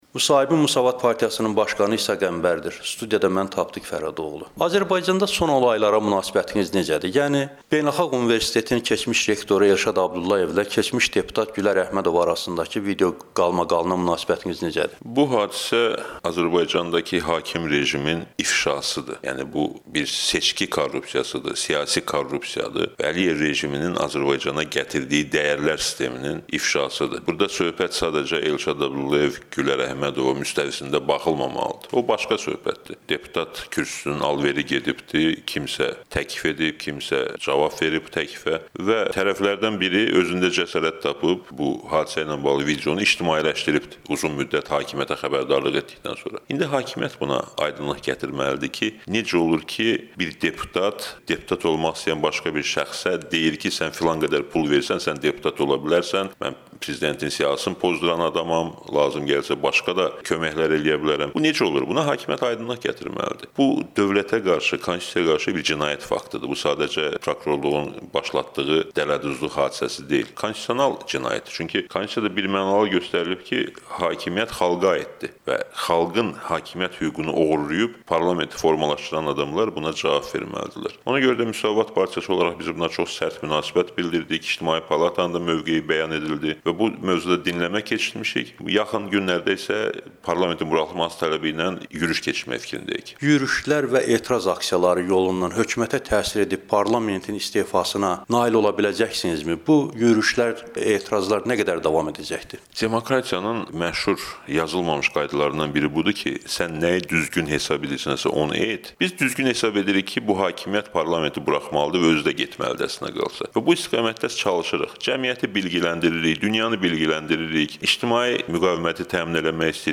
Müsavat Partiyasının başqanı İsa Qəmbərlə müsahibə